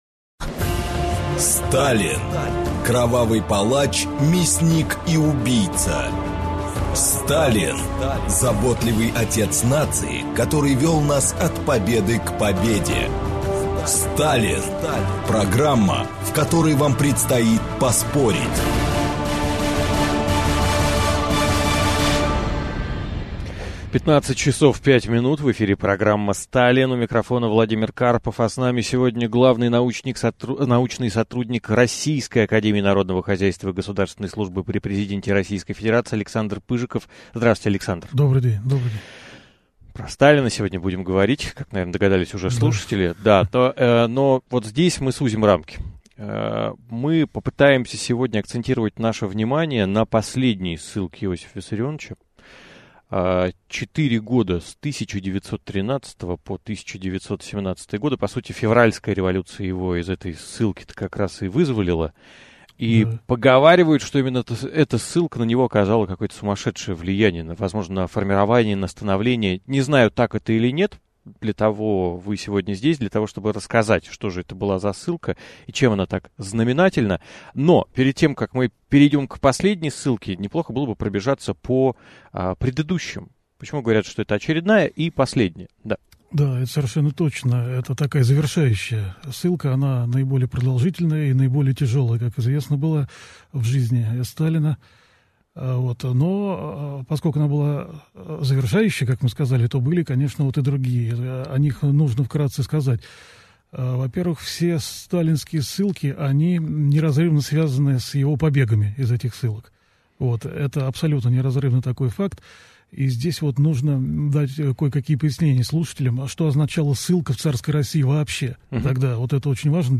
Аудиокнига 4 года до революции. Арест Сталина и сибирская ссылка | Библиотека аудиокниг